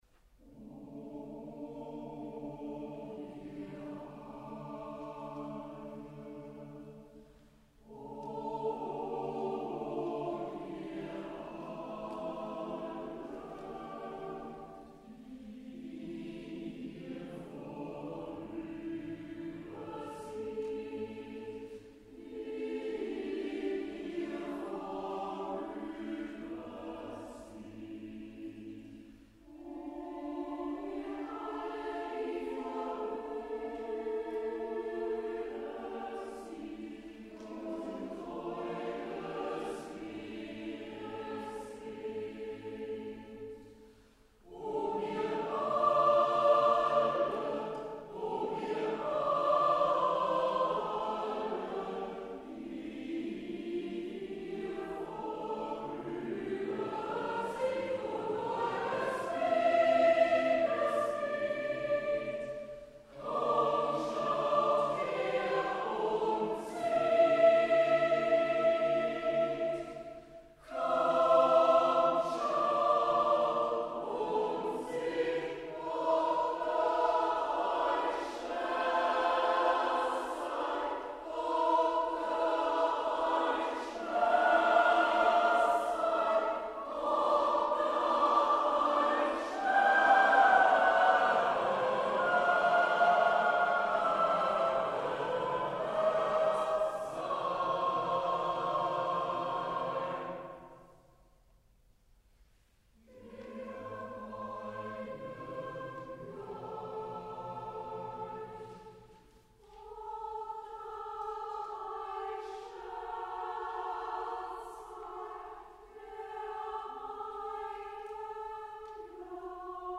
Hörbeispiele der Kantorei